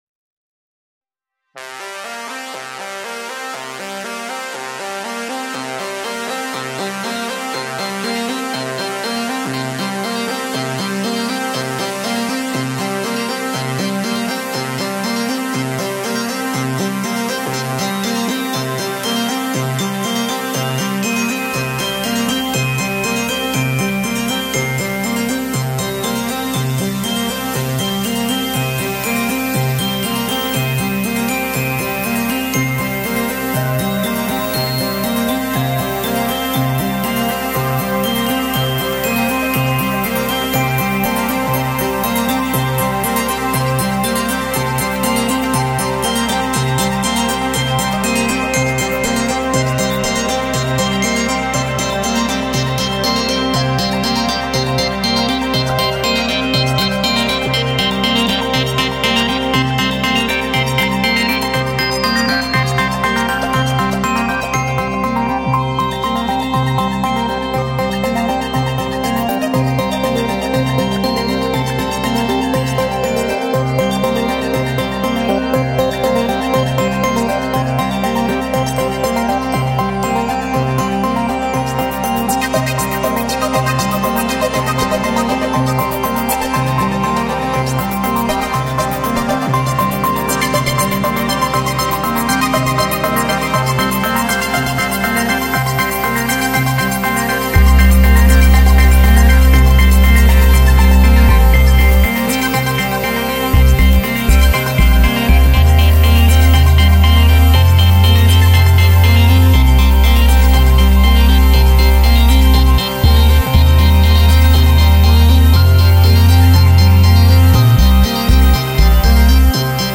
(Rock, Blues, Jazz, Hip-Hop, Electronic, and Punk)